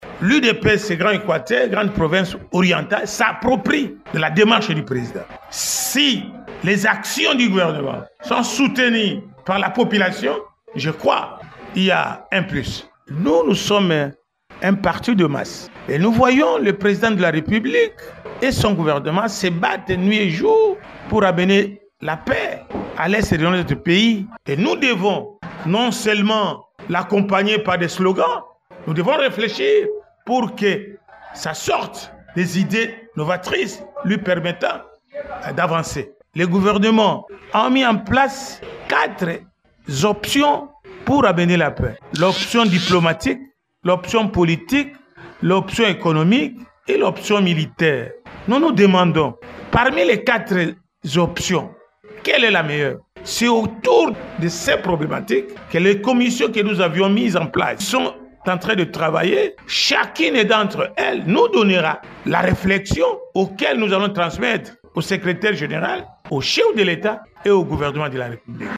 L’interfédéral Grand Équateur et Grande Orientale de l’UDPS a organisé, du 20 au 21 décembre, un forum de réflexion visant à formuler des propositions pour contribuer au retour de la paix dans l’Est de la RDC.